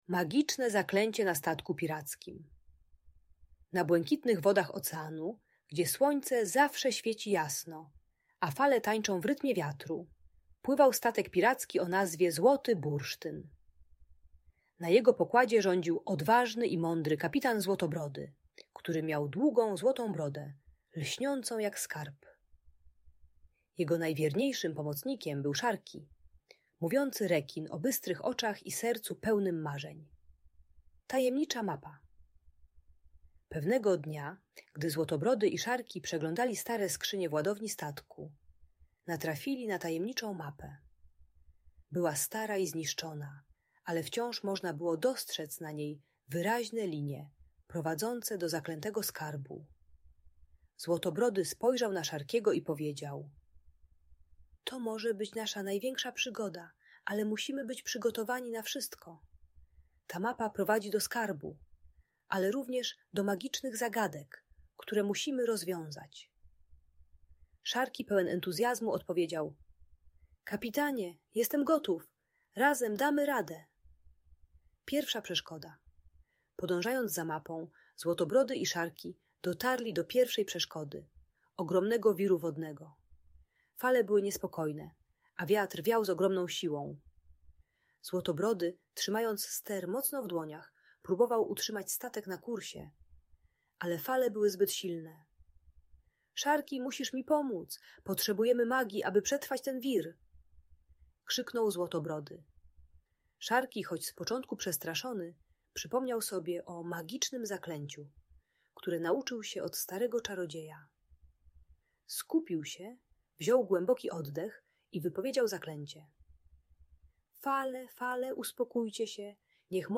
Magiczna przygoda 'Złotego Bursztynu' - Bunt i wybuchy złości | Audiobajka